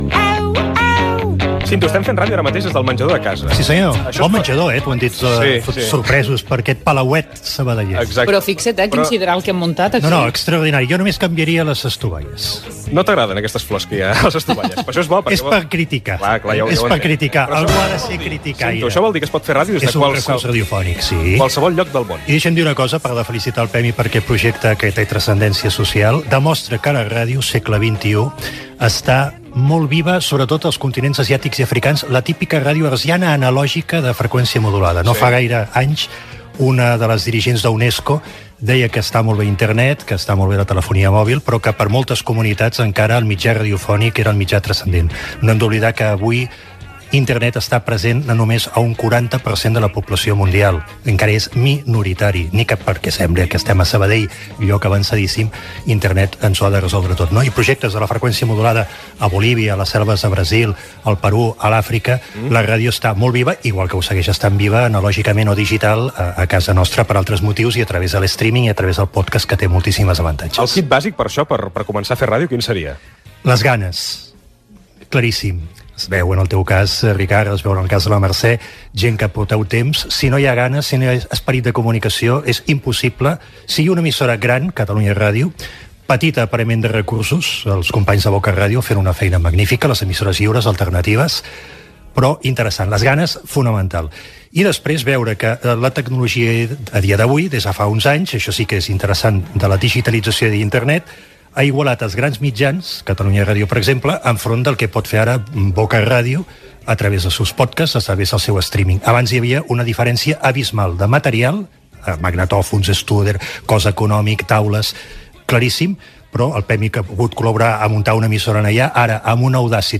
Ricard Ustrell emet un programa des de casa seva amb motiu del Dia Mundial de la ràdio 2017 .
Entreteniment
FM